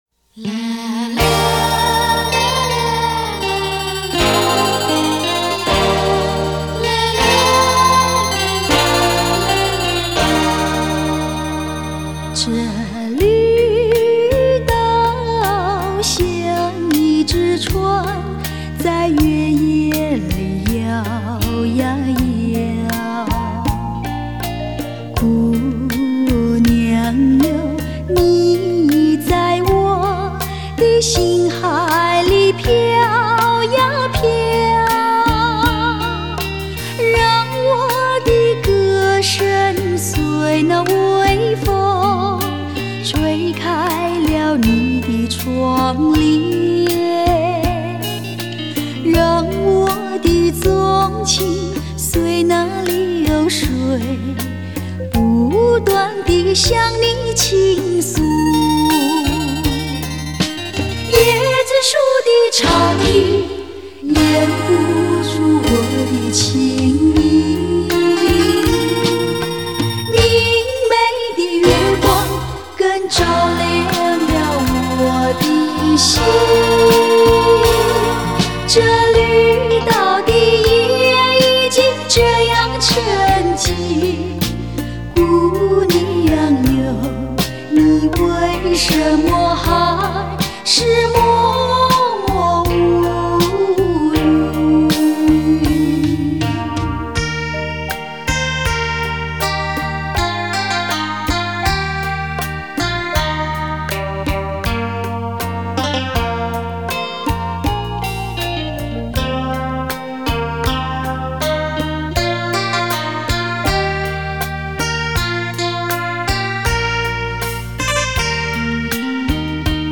低品质试听